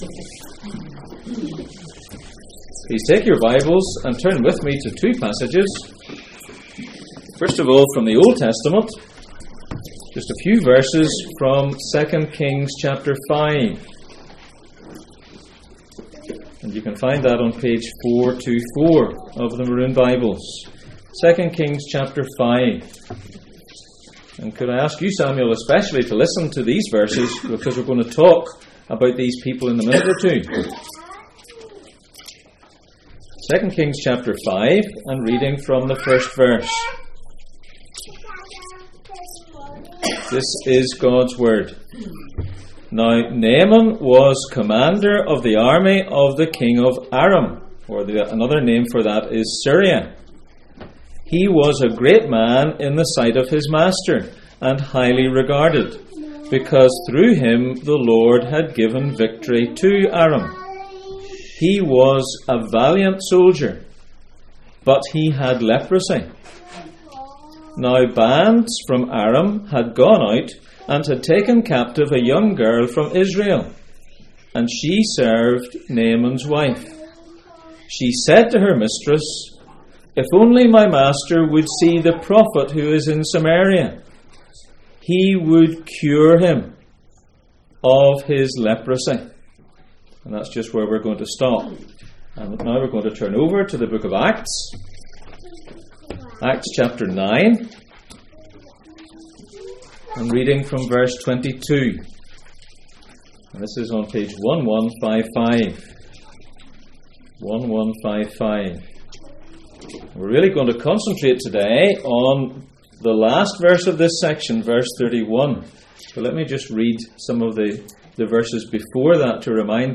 Passage: Acts 9:22-31, 2 Kings 5:1-3 Service Type: Sunday Morning